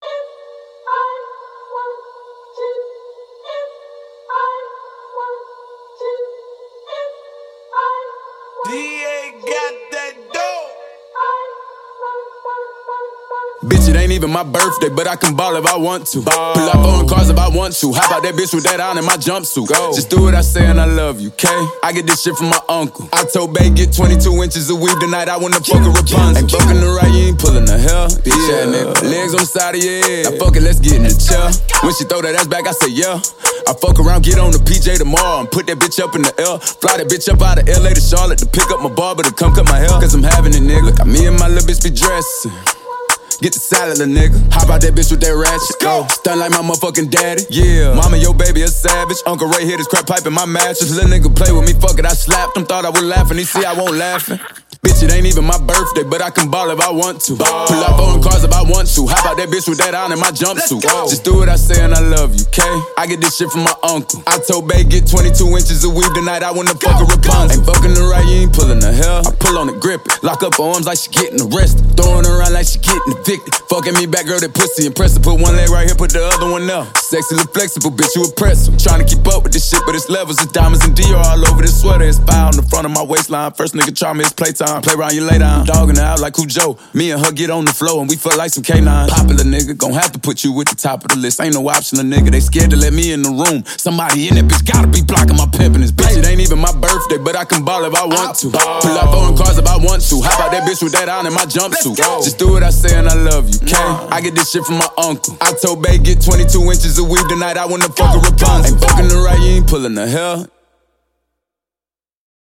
American rapper